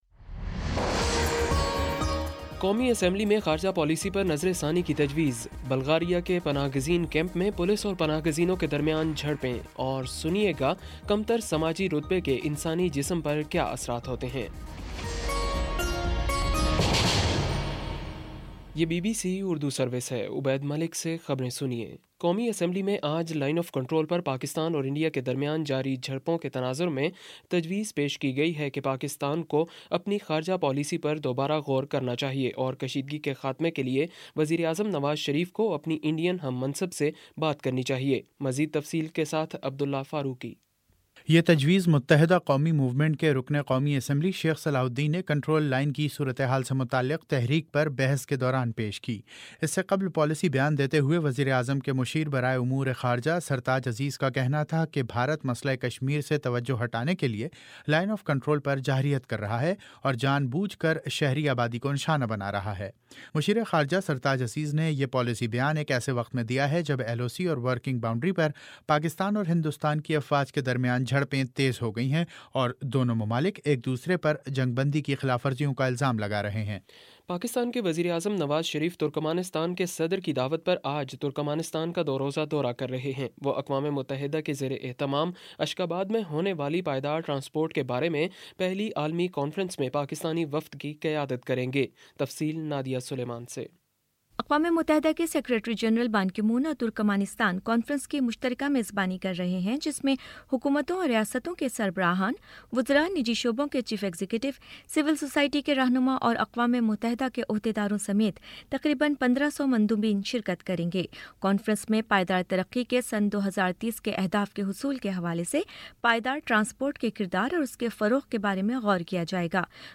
نومبر 25 : شام چھ بجے کا نیوز بُلیٹن